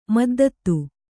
♪ maddattu